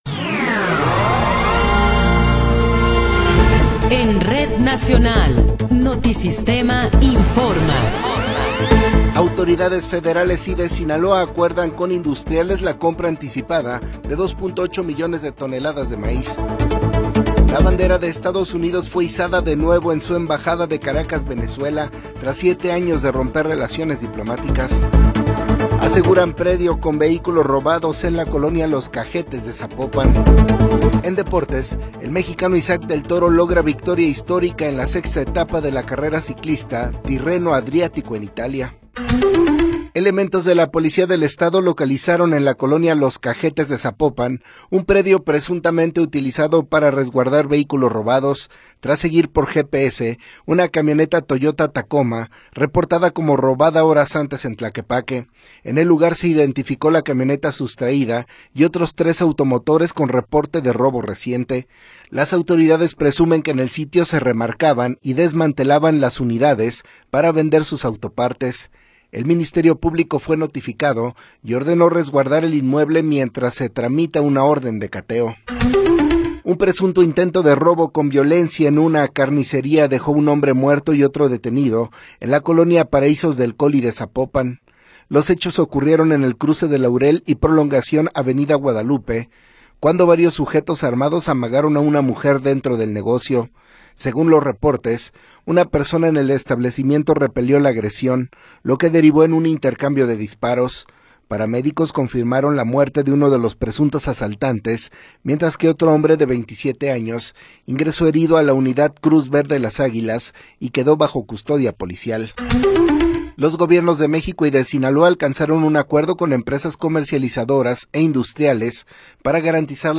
Noticiero 20 hrs. – 14 de Marzo de 2026
Resumen informativo Notisistema, la mejor y más completa información cada hora en la hora.